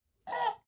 铜管 舒缓
声道单声道